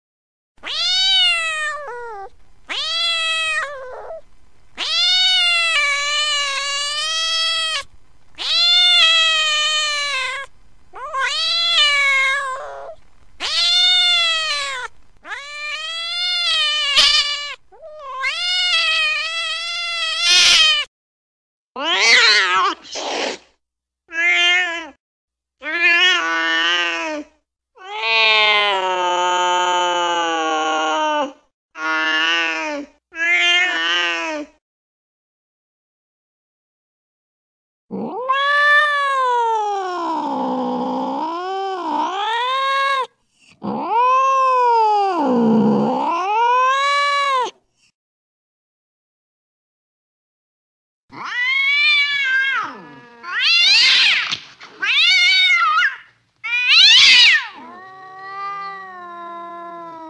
Tiếng Mèo vờn Chuột MP3